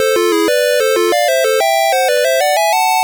One of the jingles that plays at the start of a level
Source Recorded from the Sharp X1 version.